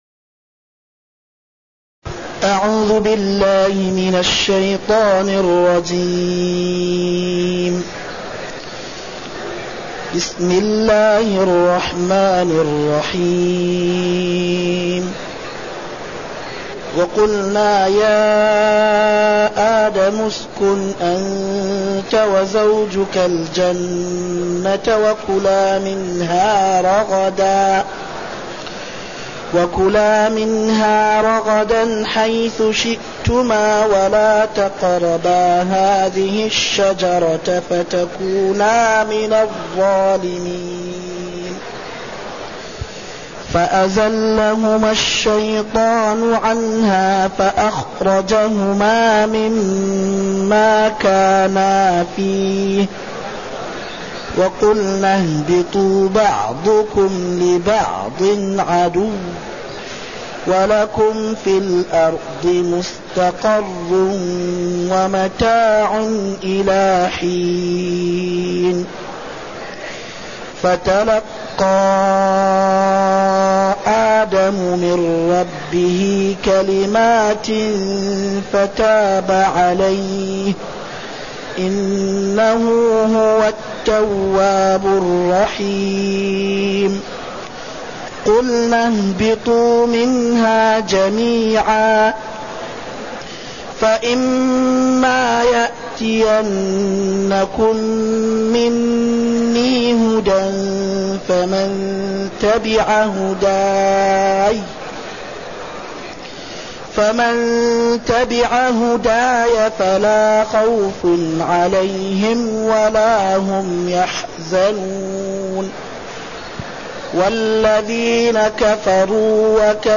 تاريخ النشر ١ محرم ١٤٢٨ هـ المكان: المسجد النبوي الشيخ